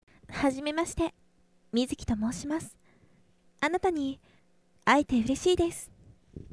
ネット声優に50のお題はこちらお借りしました